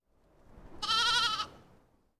Balido de oveja 1